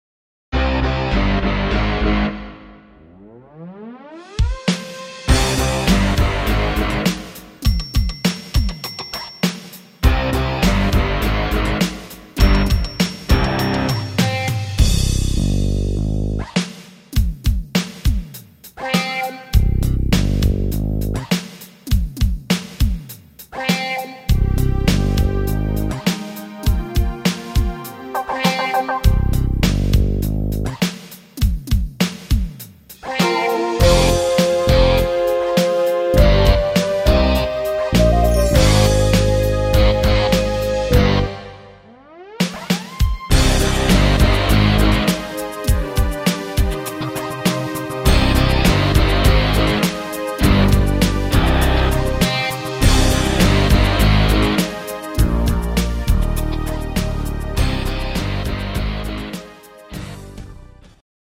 Rhythmus  Pop
Art  Englisch, Pop, Weibliche Interpreten